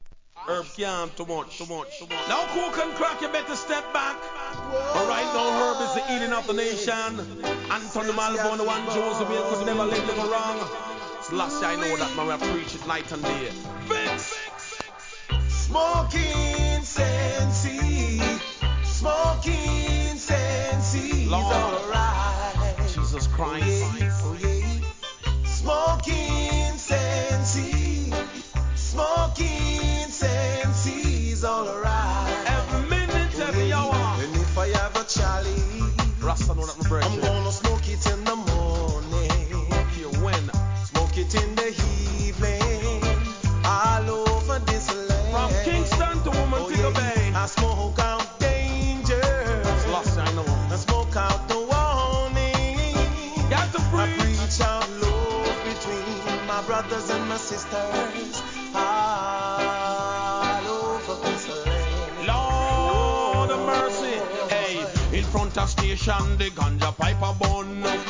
¥ 1,100 税込 関連カテゴリ REGGAE 店舗 ただいま品切れ中です お気に入りに追加 1